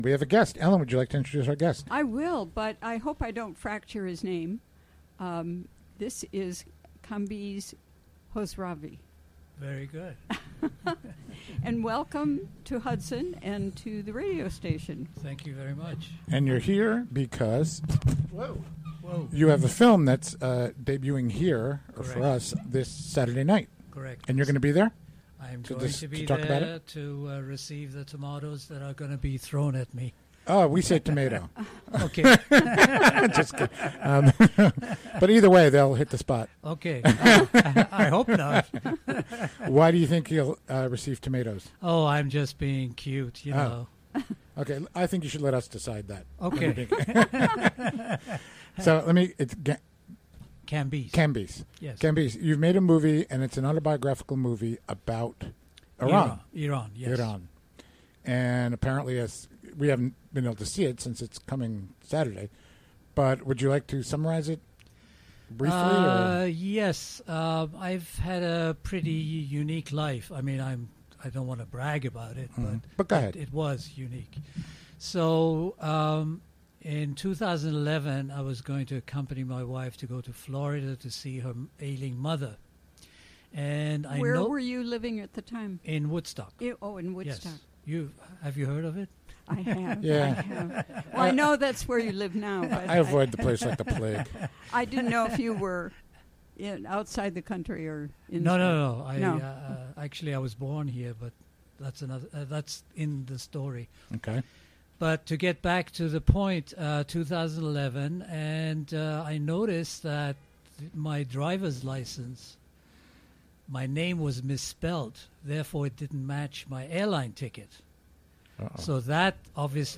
Recorded during the WGXC Afternoon Show on Thursday, June 23, 2016.